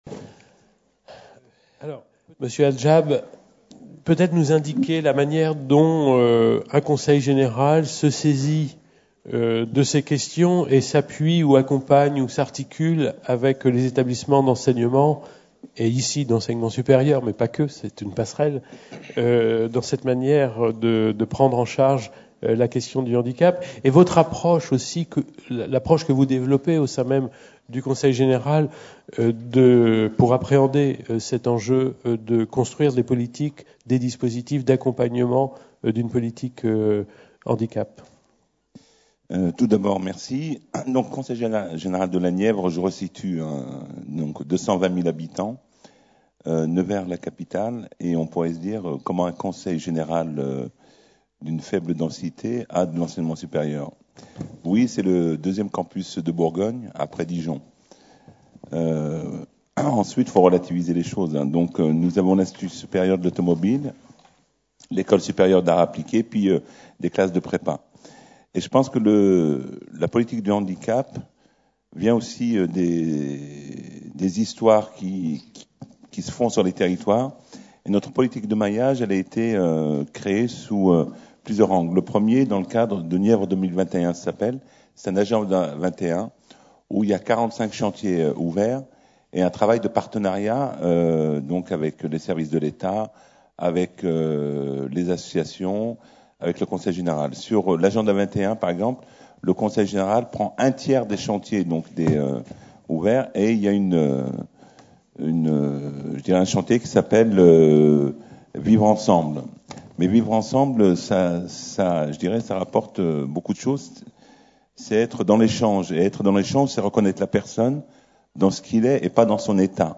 Table ronde 3 : La réussite d’une politique handicap des établissements de l’enseignement supérieur et de recherche grâce au maillage territorial | Canal U